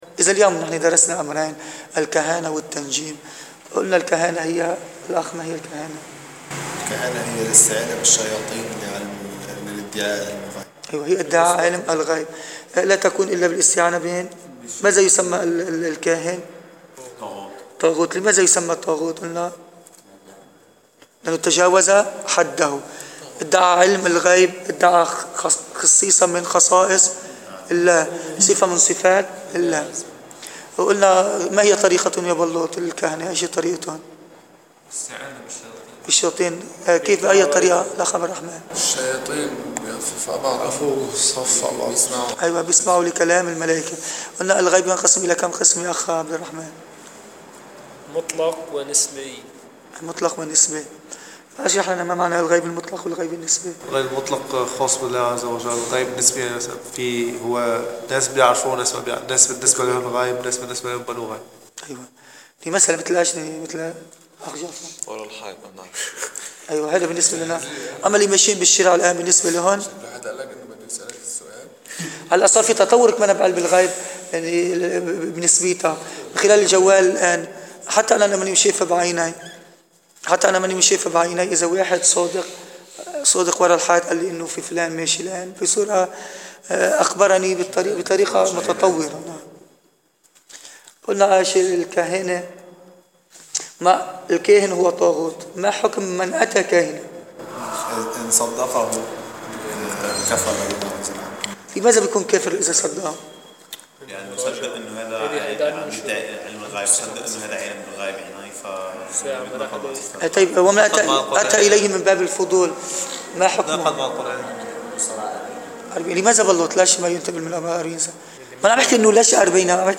دروس عقيدة